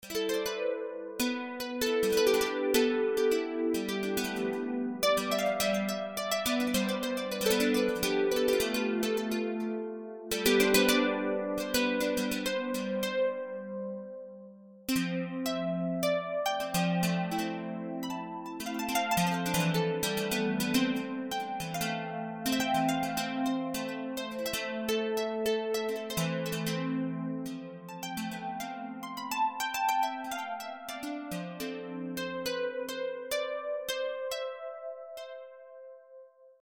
というわけで、FL StudioとMIDIキーボードを使って、即興曲を弾いてみました。
適当に、白鍵盤だけを弾き（Ｃメジャースケール）、クォンタイズをかけ、メロディを微調整しただけのものです。
適当に白鍵盤だけを弾いたラクガキ即興曲」を聞いてみる？
デタラメに弾いても、なんとなく音楽になるっぽいわけです。